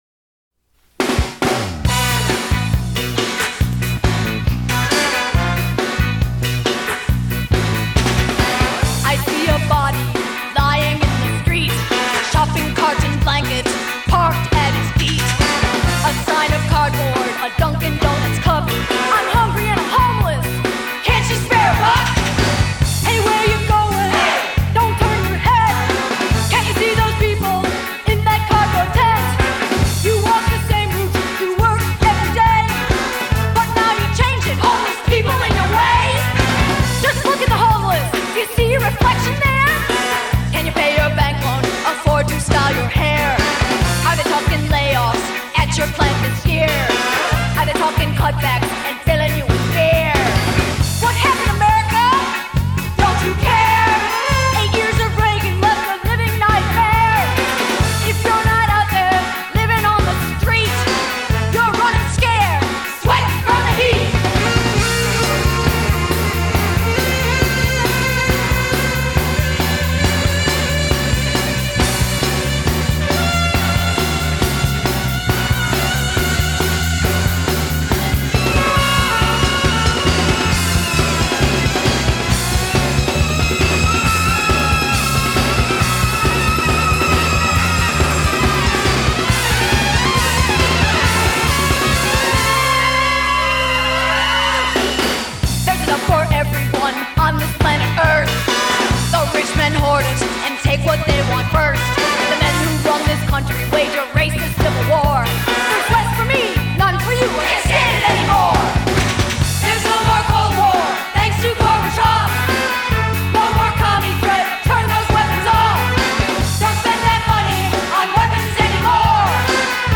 hi-fi
Sung by the writers except as noted.